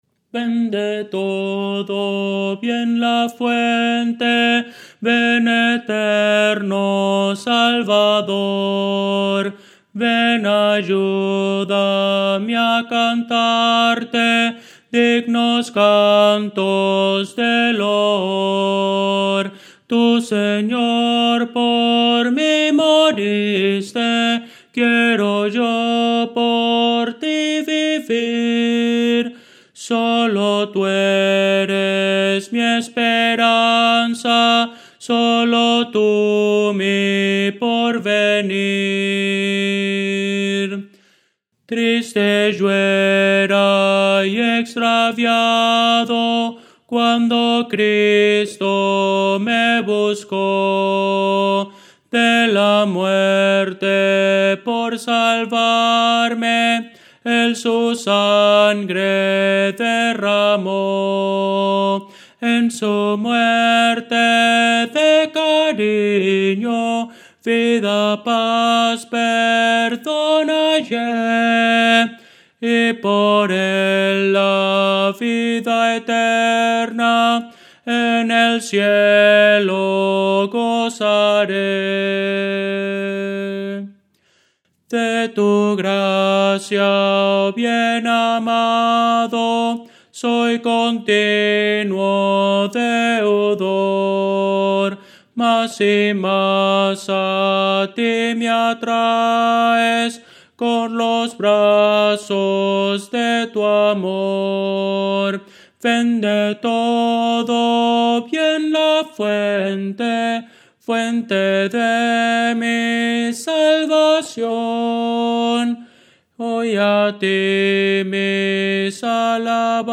Voces para coro
Tenor – Descargar